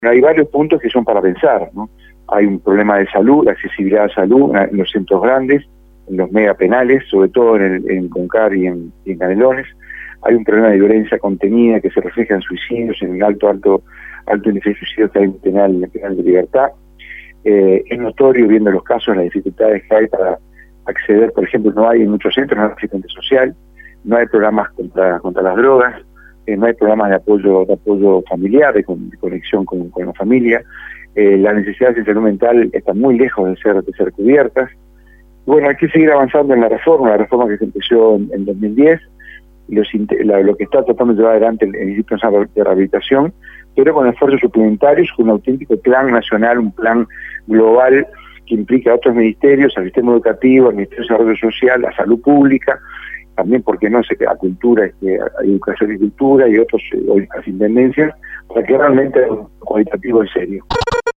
El Comisionado Parlamentario para el Sistema Carcelario, Juan Miguel Petit, dijo a Rompkbzas que están haciéndose cosas, pero que todavía falta mucho.